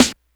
snare04.wav